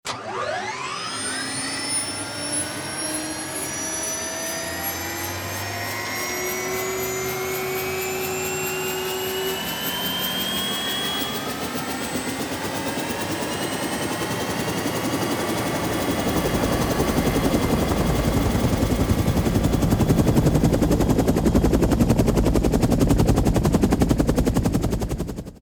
Tiếng Khởi động động cơ Máy bay Cánh quạt
Thể loại: Tiếng xe cộ
Description: Tiếng Khởi động động cơ Máy bay Cánh quạt là âm thanh máy móc động cơ của một cánh quạt lớn đang bắt đầu khởi động, tiếng cánh quạt của trực thăng chạy vù vù tạo nên âm thanh lớn, tiếng máy bay khởi động các động cơ và chuẩn bị bay lên không trung, là âm thanh cánh quạt trực thăng chân thực.
Tieng-khoi-dong-dong-co-may-bay-canh-quat-www_tiengdong_com.mp3